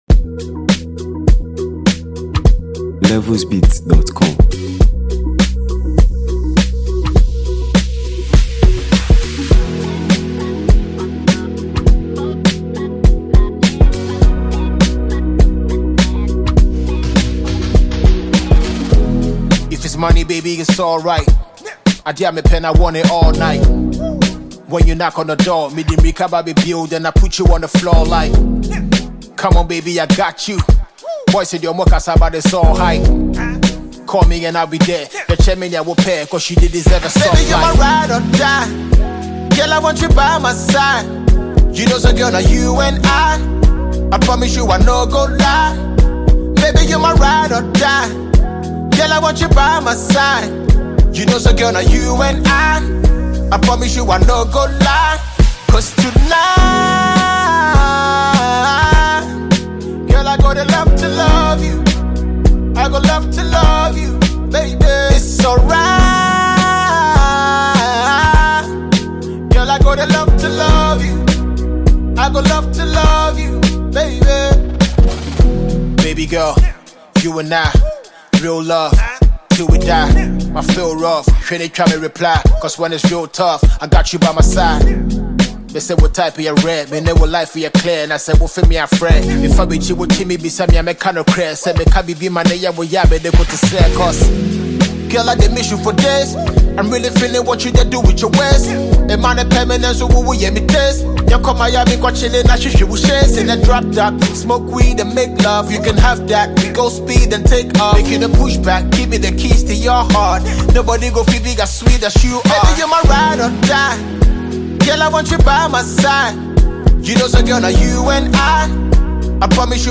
Ghana Music 2025 2:40
Renowned Ghanaian rap legend and award-winning songwriter
clean production